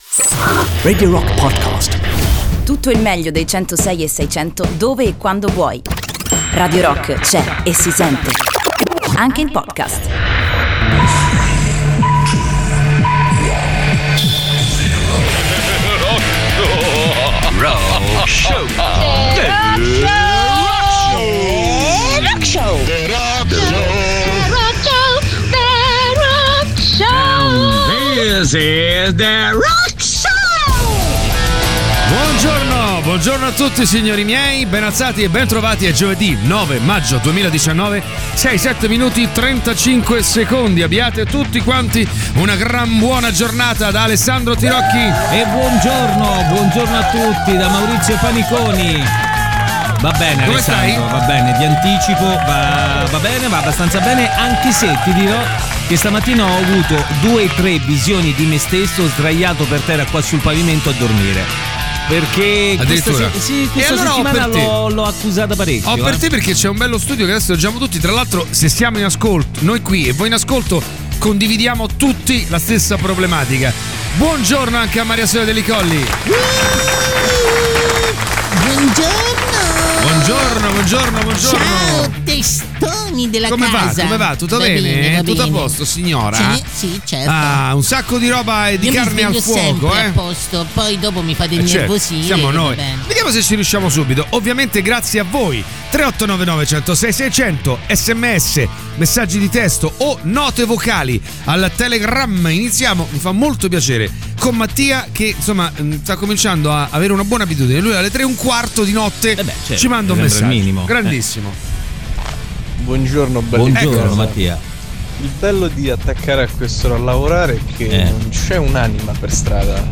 in diretta dalle 06.00 alle 08.00 dal Lunedì al Venerdì sui 106.6 di Radio Rock